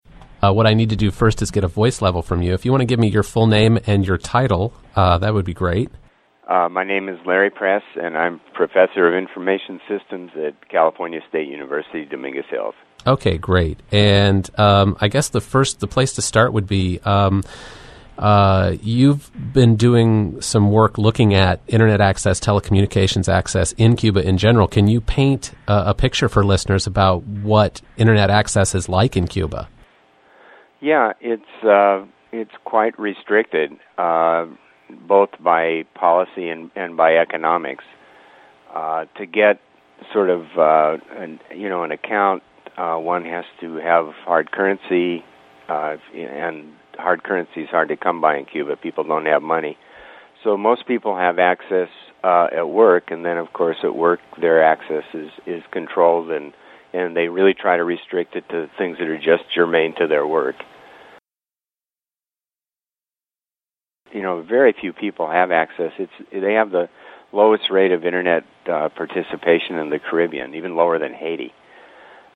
The interview was recorded over the phone, and although only a few seconds were used, it took over five minutes.
This is a portion of the original interview. The first sound bite is full of "ums" and hesitations, which were edited out in the final version.
(I separated it by three seconds of silence in this excerpt).